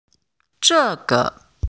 四声の単語
「這個 zhè ge」の発音